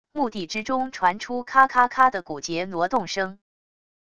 墓地之中传出咔咔咔的骨节挪动声wav音频